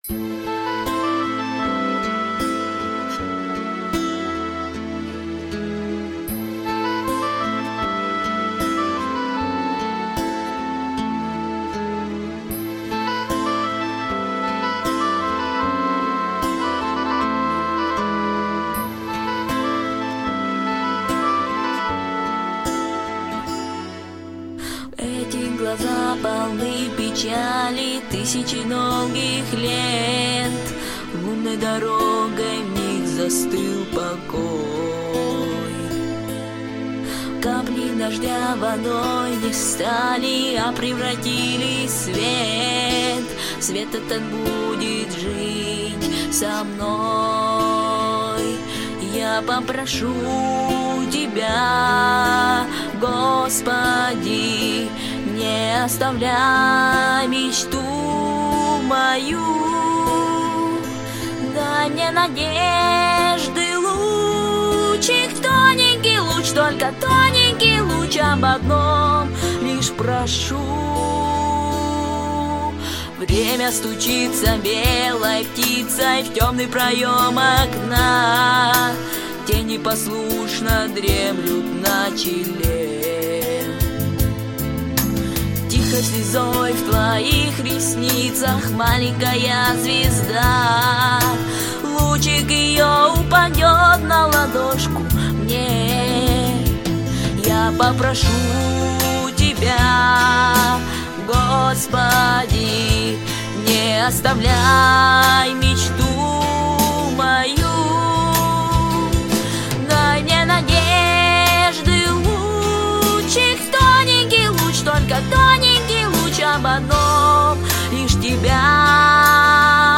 • Категория: Детские песни
христианские песни